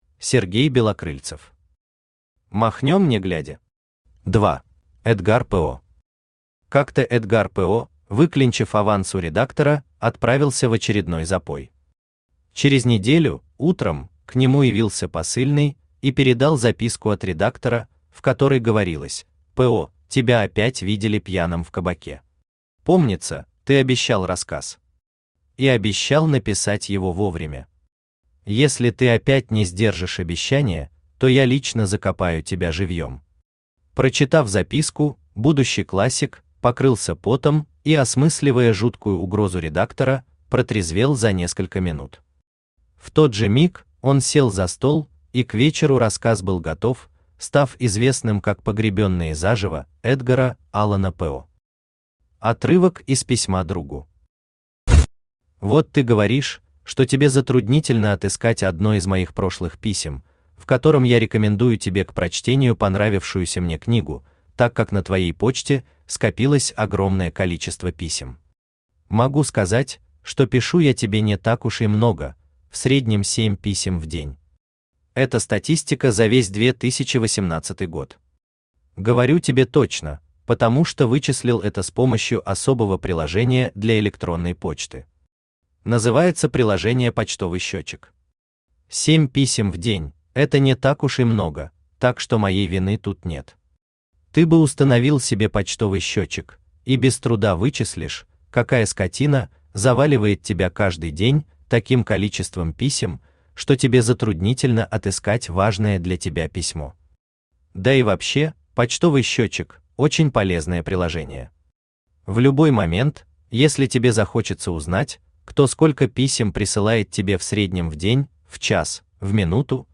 Аудиокнига Махнём не глядя? – 2 | Библиотека аудиокниг
– 2 Автор Сергей Валерьевич Белокрыльцев Читает аудиокнигу Авточтец ЛитРес.